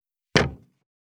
188,地震,引っ越し,荷物運び,段ボール箱の中身,部署移動,ゴロゴロ,ガタガタ,ドスン,バタン,ズシン,カラカラ,ギィ,ゴトン,
コップ効果音物を置く
コップ